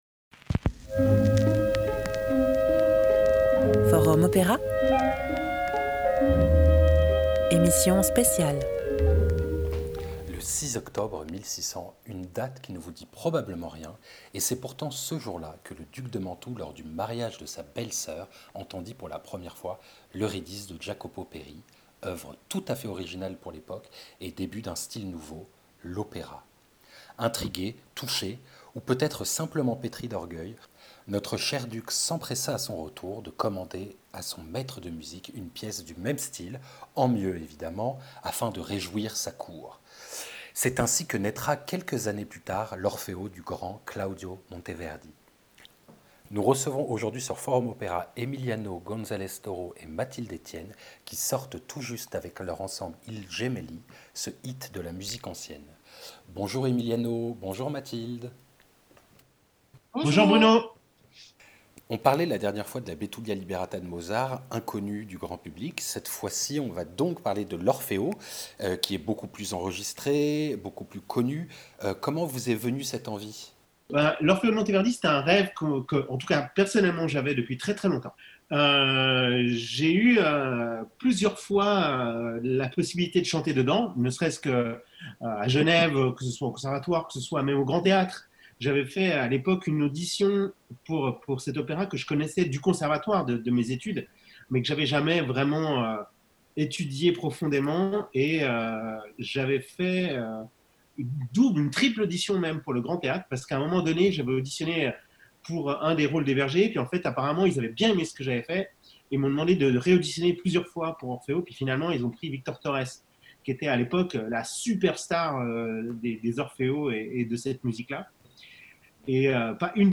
Naissance d'un Orfeo, entretien